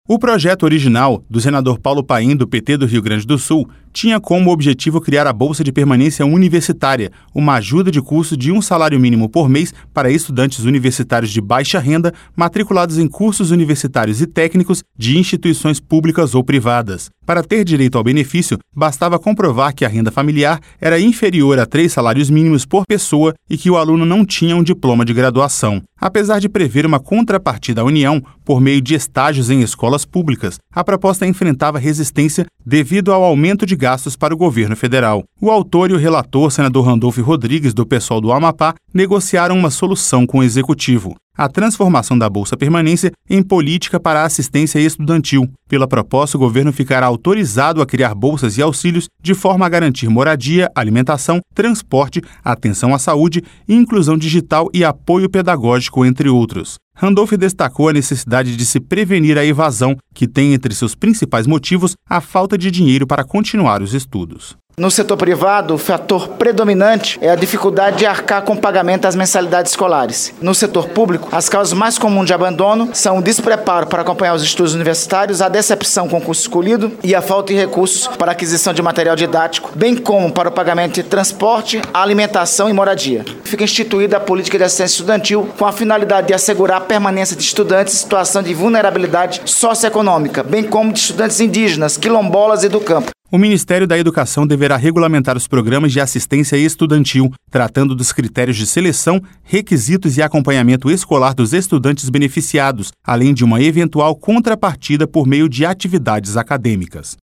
LOC: A PROPOSTA, MODIFICADA PARA SE TORNAR UMA POLÍTICA DE ASSISTÊNCIA ESTUDANTIL, SEGUE AGORA PARA A CÂMARA DOS DEPUTADOS. REPÓRTER